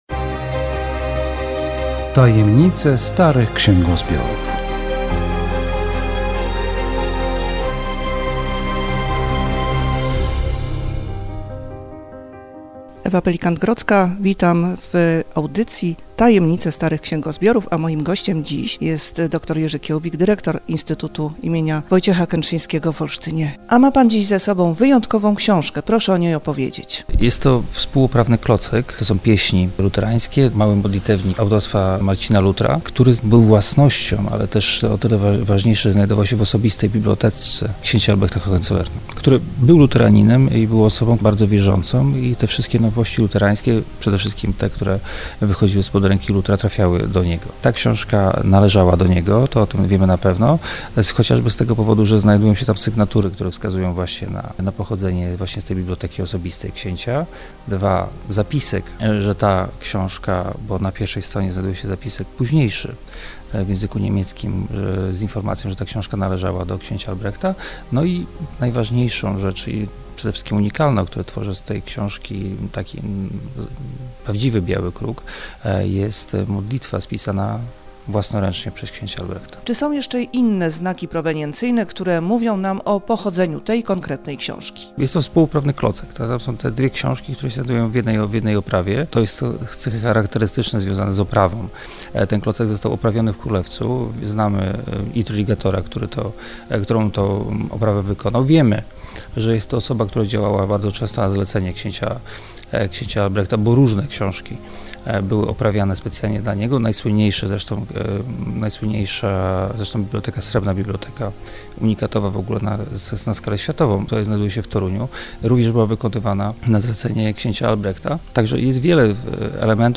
Audycja radiowa "Tajemnice starych księgozbiorów".